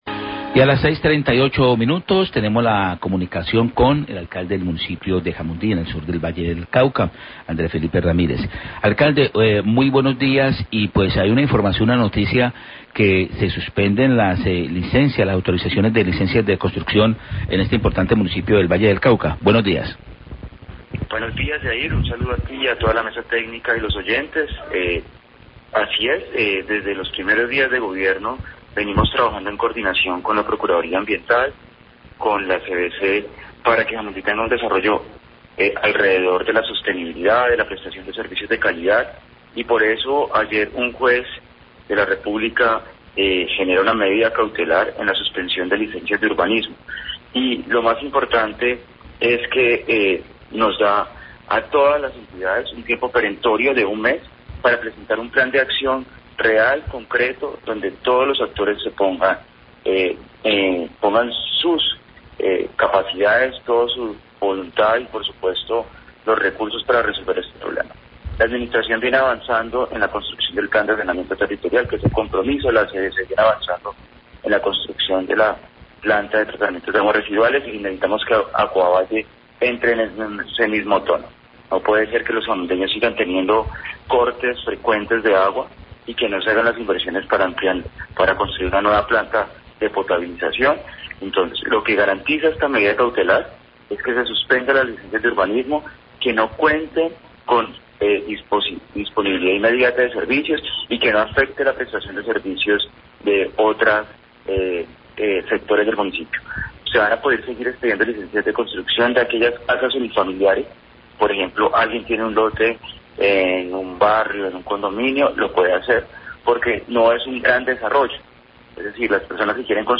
Radio
El alcalde de Jamundí, Andrés Felipe Ramírez, habla del fallo judicial que suspende las licencias de urbanismo a grandes desarrollos habitacionales.Destaca apoyo de la CVC para el desarrollo sosotenible del municipio y critica a Acuavalle por los constantes cortes del servicio de acuedcuto.